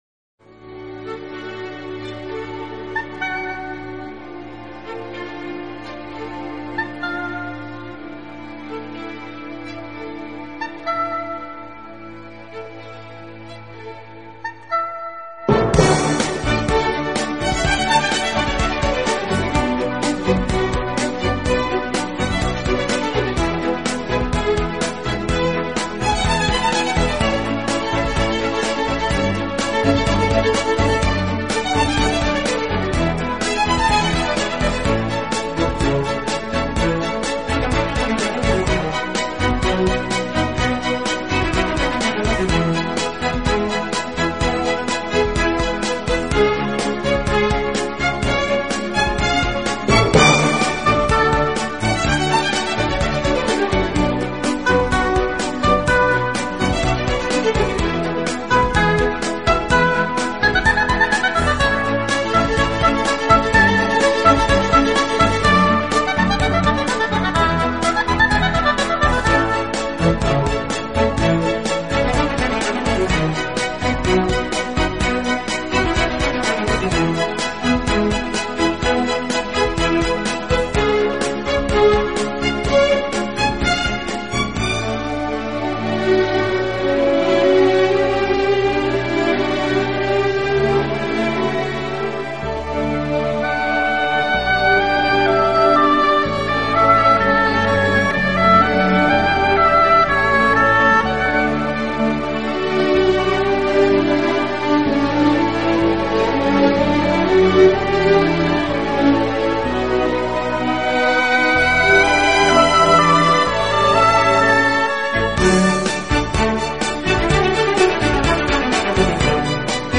音乐类型：Classical